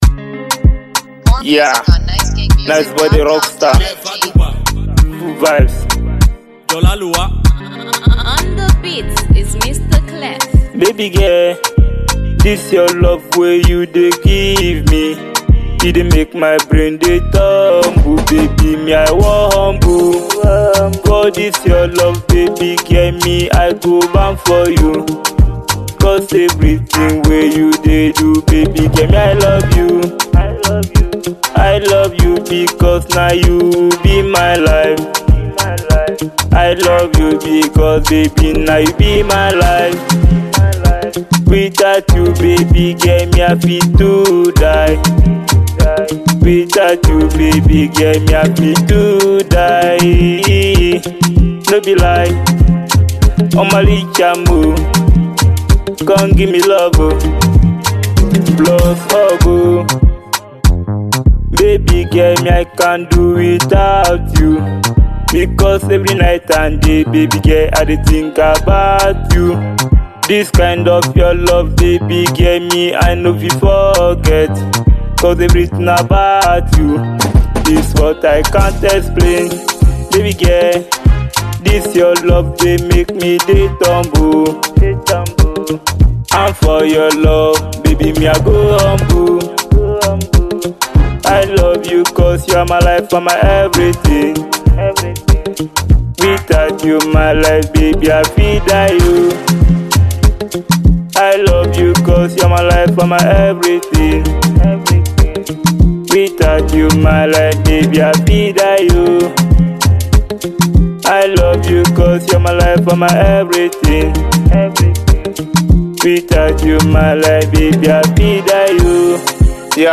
02:32 Genre : Afro Pop Size